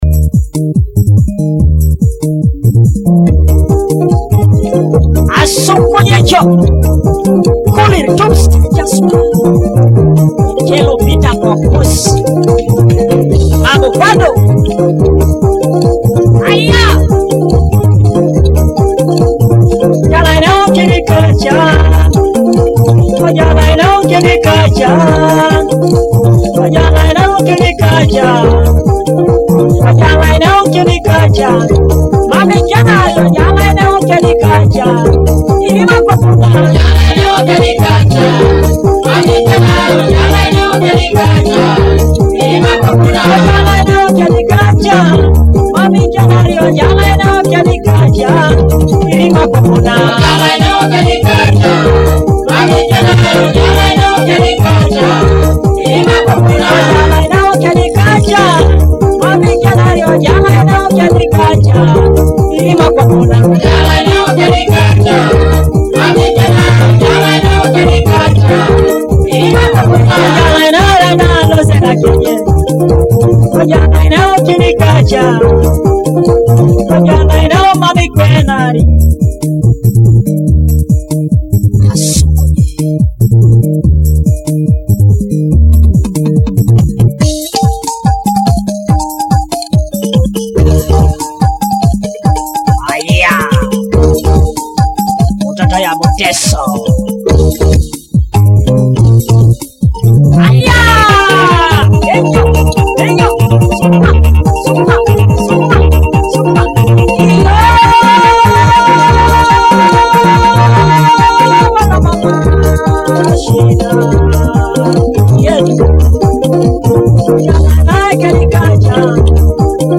featuring joyful Ateso cultural and traditional rhythms
Akogo (thumb piano)
Adungu (arched harp)